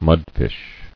[mud·fish]